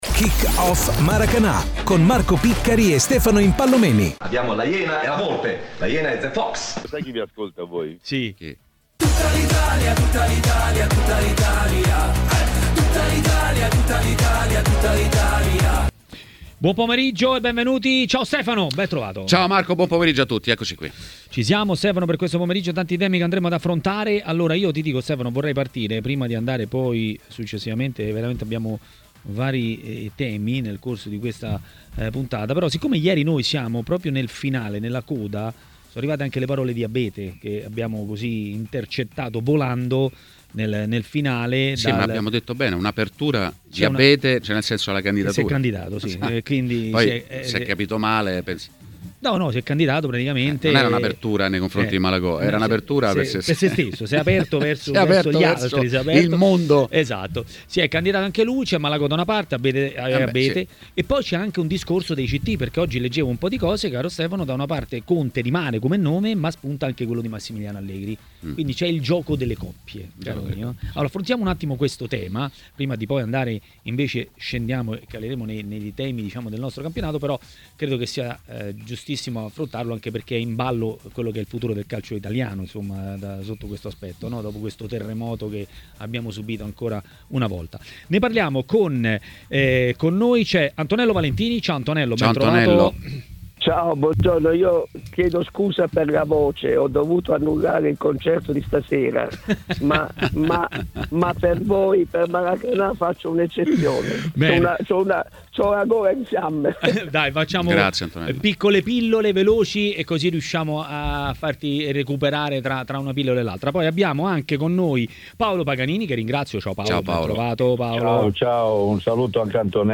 Ospite di Maracanà, nel pomeriggio di TMW Radio,